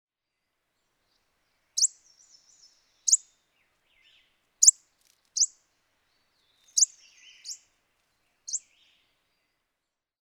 lemon-rumped warbler
Phylloscopus chloronotus